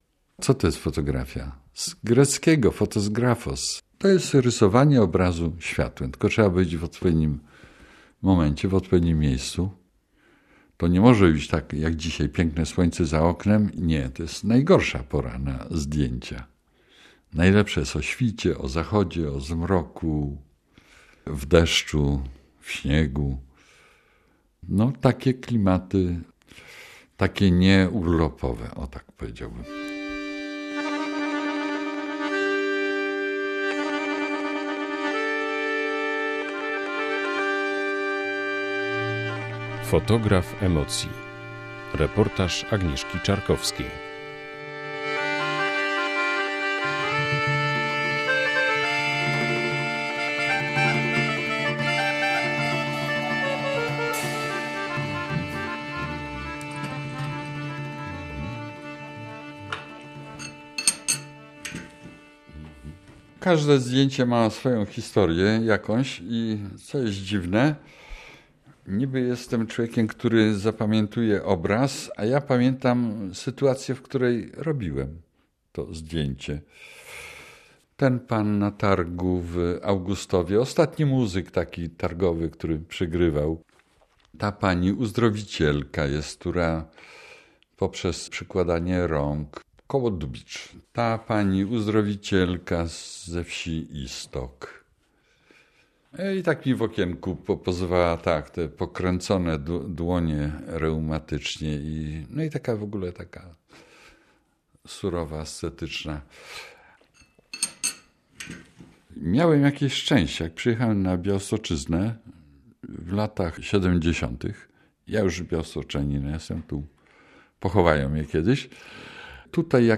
"Fotograf emocji" - reportaż